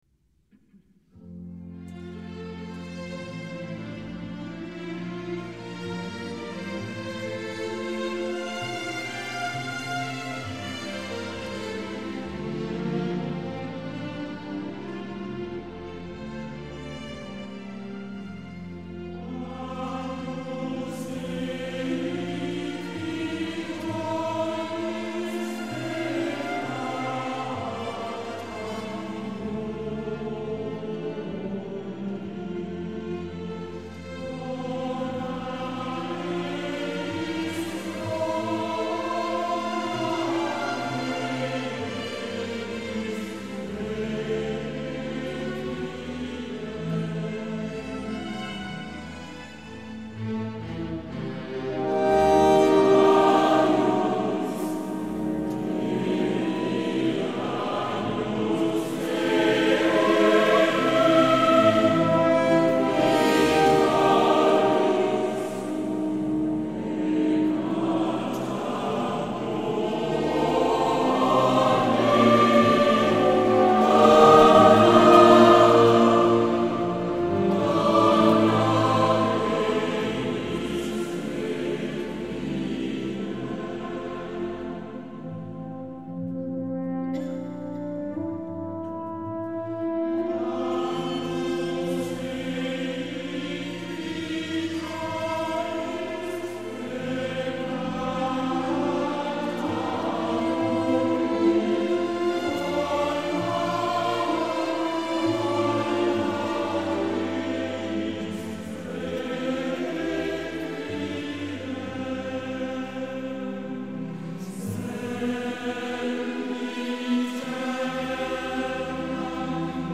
St. Jodokus - Immenstaad - 31. März 2012